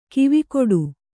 ♪ kivikoḍu